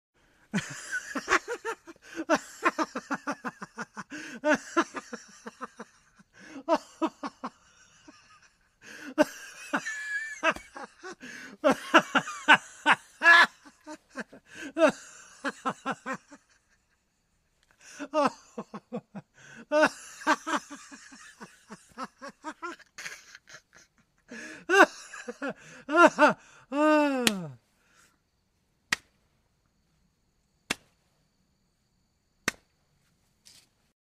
دانلود صدای خندیدن مرد 2 از ساعد نیوز با لینک مستقیم و کیفیت بالا
جلوه های صوتی